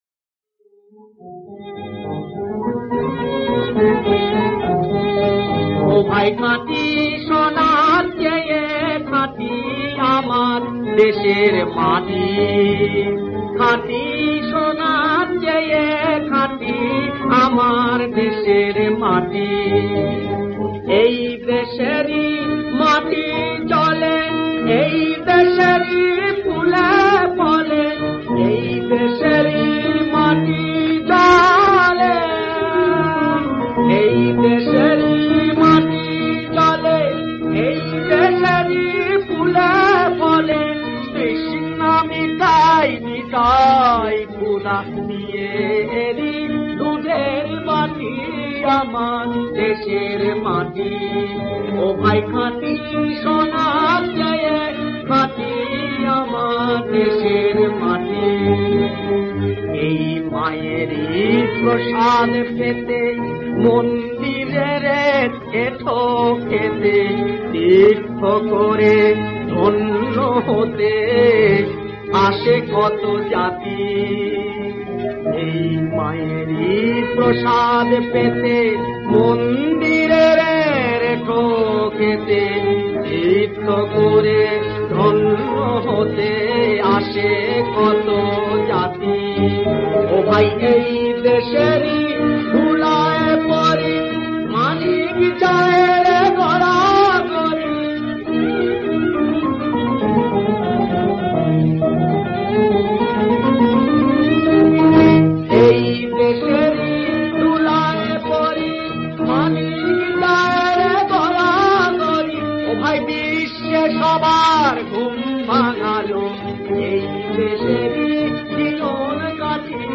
• সুরাঙ্গ: বাউল
• গ্রহস্বর : গা।